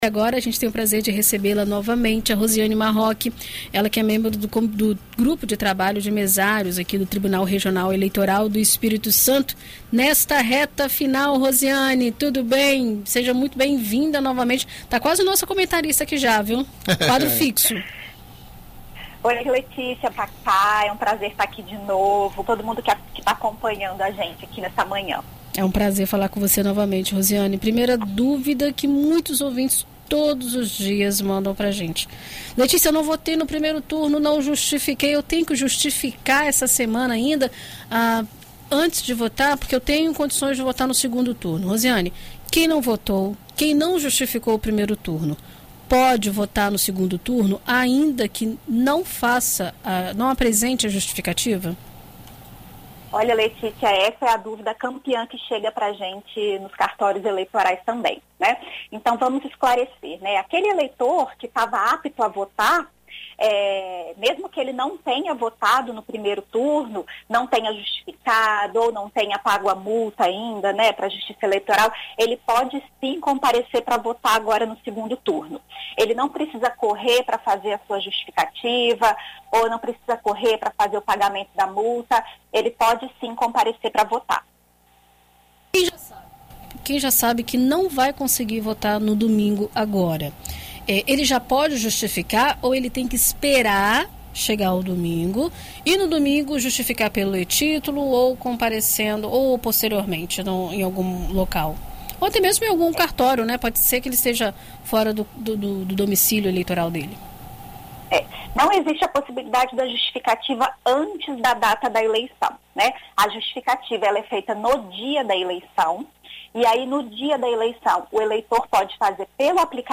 Marcadas para ocorrerem no próximo domingo (30), as votações do segundo turno das eleições deste ano estão com esquema montado pelo Tribunal Superior Eleitoral (TSE) e os tribunais regionais. Em entrevista à BandNews FM Espírito Santo